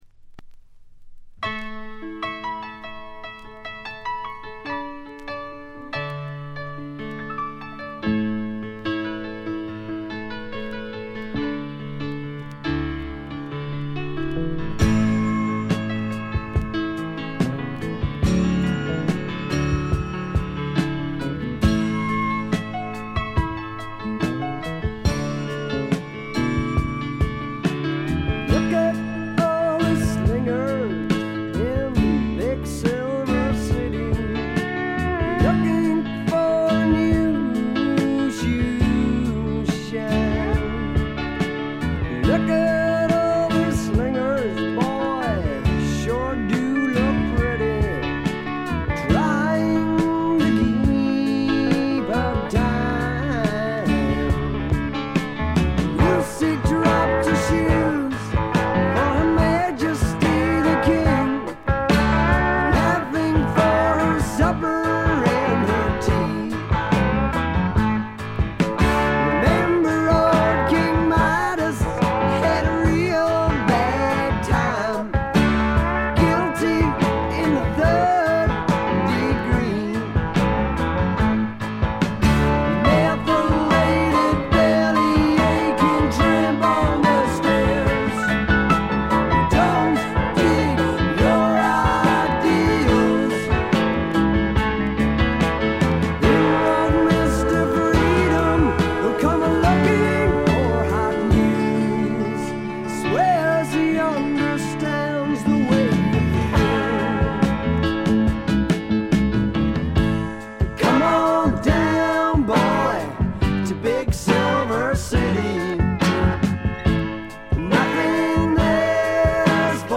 ところどころで細かいチリプチ。
試聴曲は現品からの取り込み音源です。